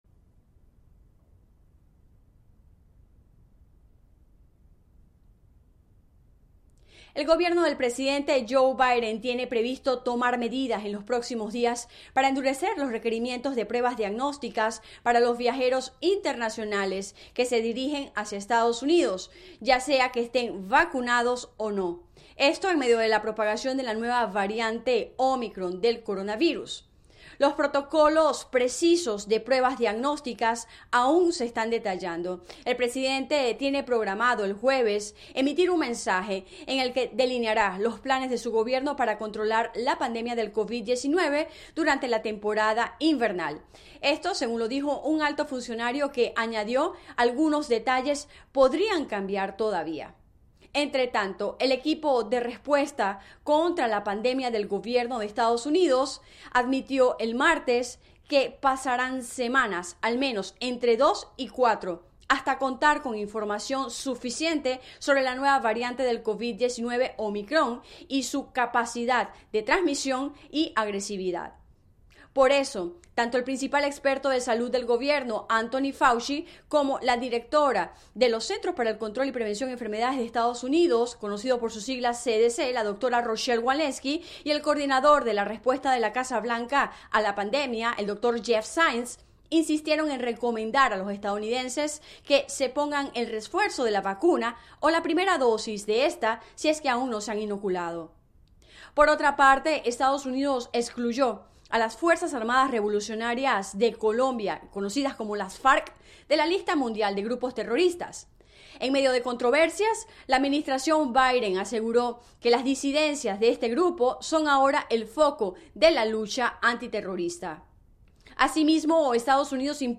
La agenda del día [Radio]